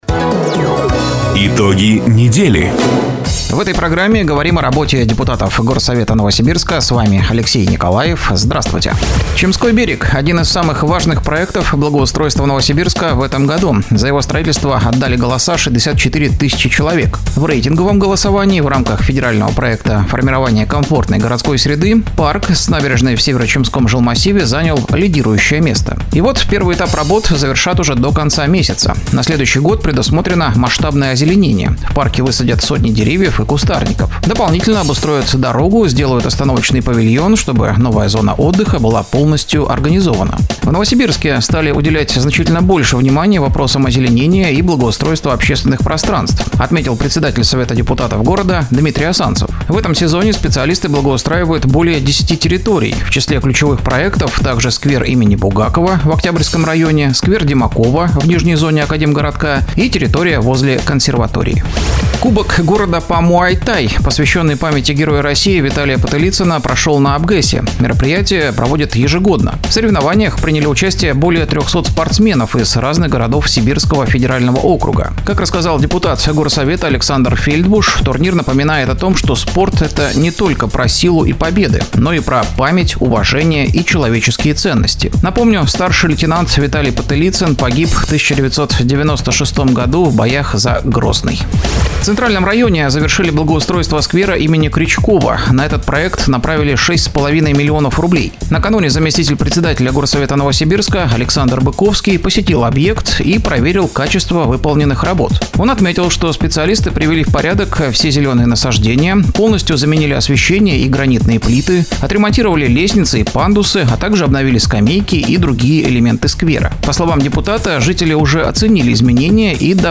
Запись программы "Итоги недели", транслированной радио "Дача" 04 октября 2025 года